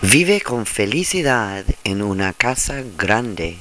Audio - Spanish Optimnem Spanish includes hundreds of authentic, native-spoken Spanish audio recordings.